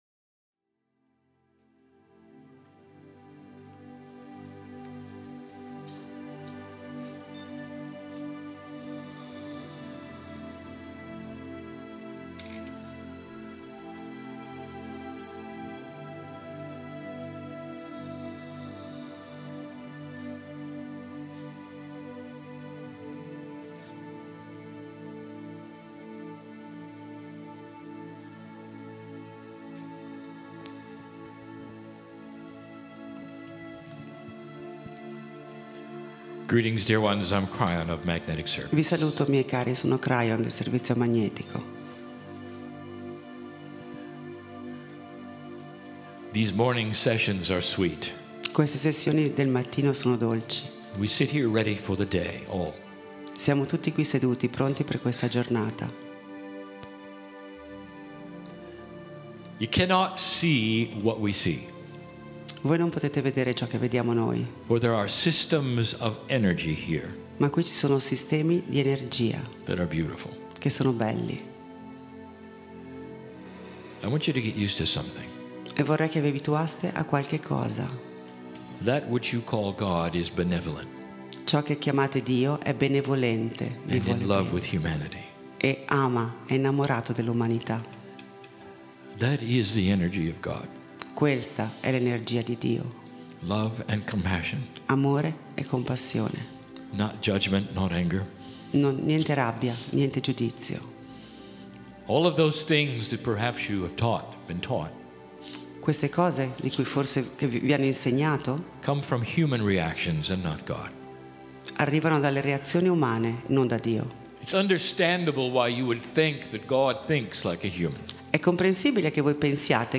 Bologna, Italy Saturday- Sunday - April 18, 19, 2015 Kryon Channelling